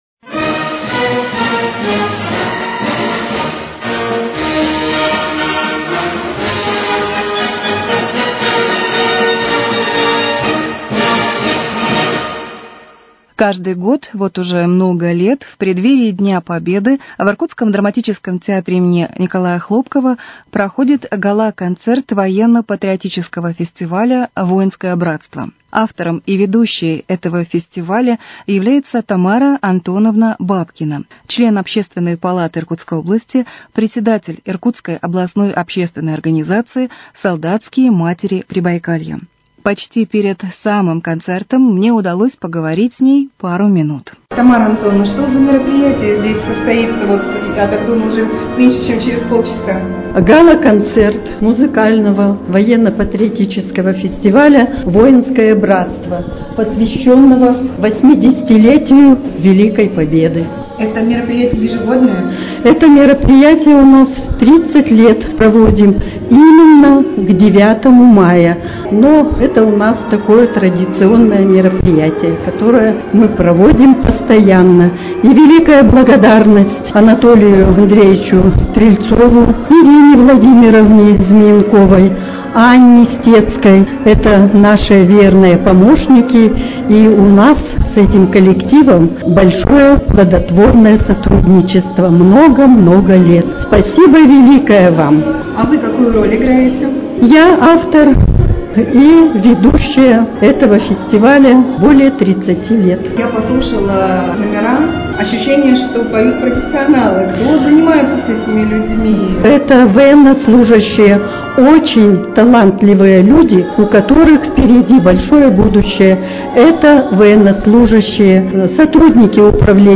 Репортаж с гала-концерта музыкального военно- патриотического фестиваля "Воинское братство"